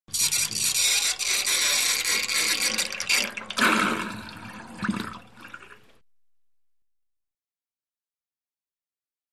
Drain, Sink | Sneak On The Lot
Water Draining With Funny Squeak